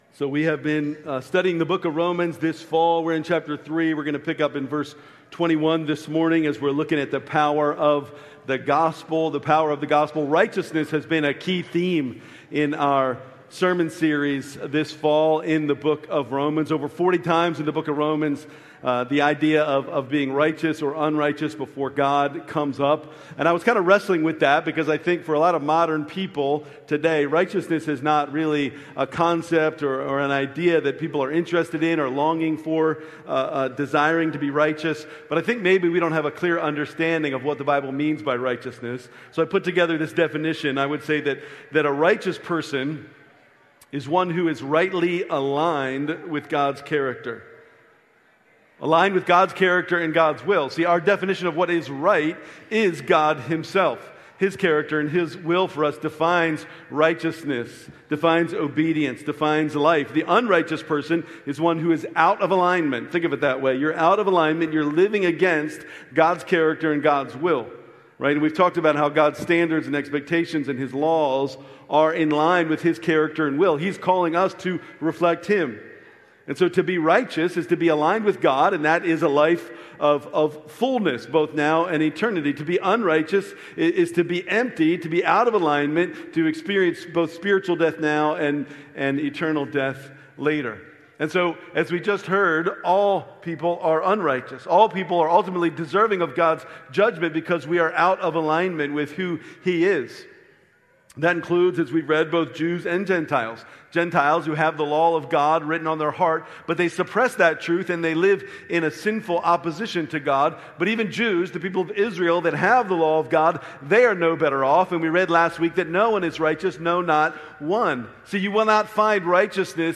October 19, 2025 Worship Service Order of Service: